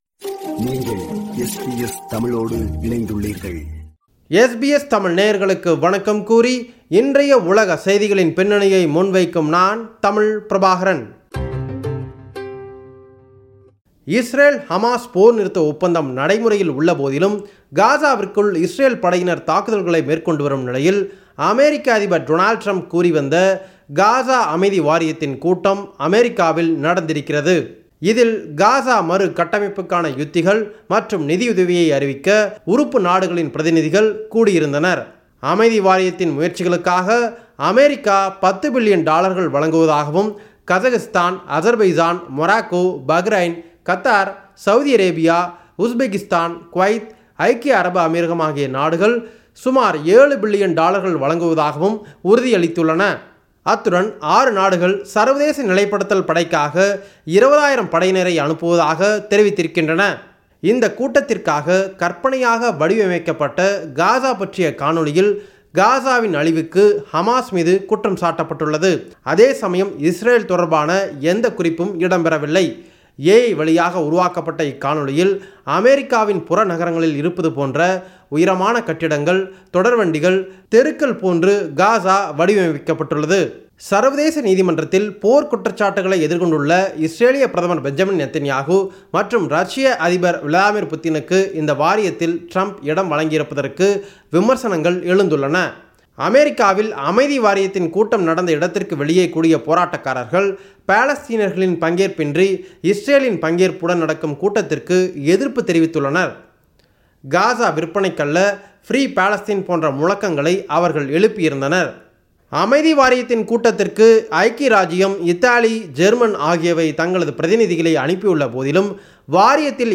உலகம்: இந்த வார செய்திகளின் தொகுப்பு